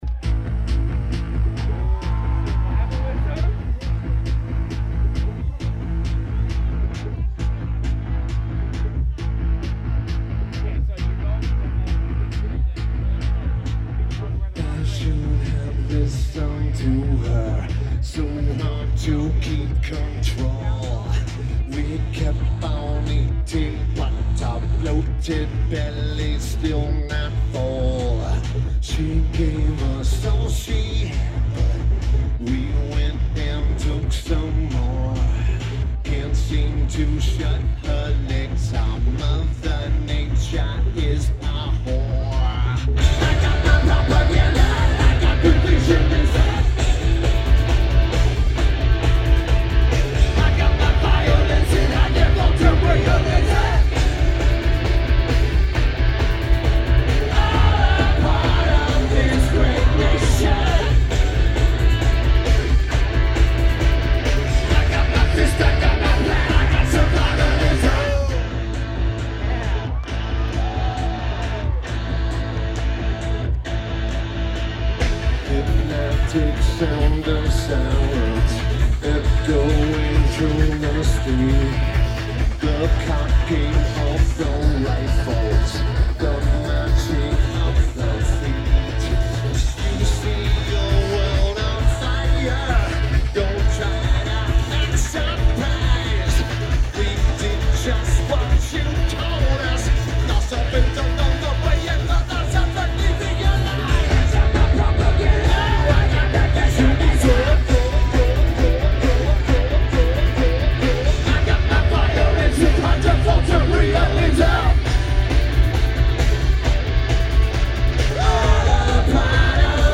McMenamins Edgefield
Lineage: Audio - AUD (AT853 (4.7k mod) + Sony PCM-A10)